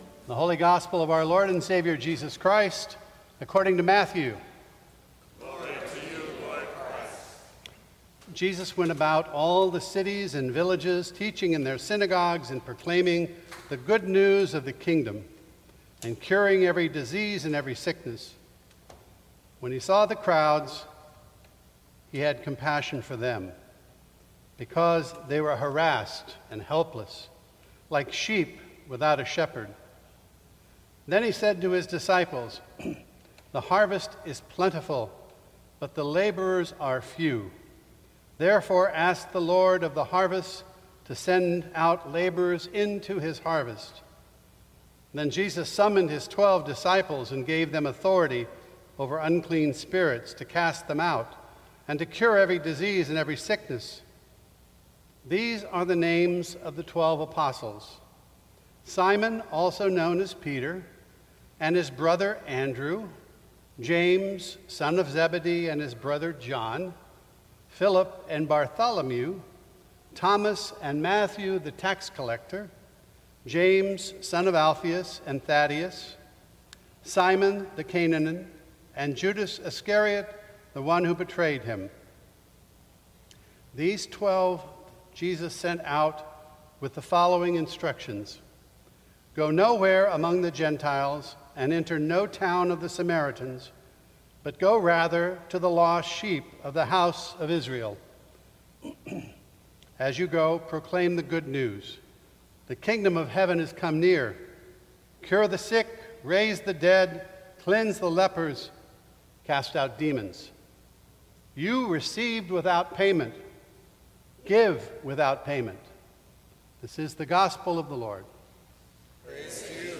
Sermons from St. Cross Episcopal Church Called Out.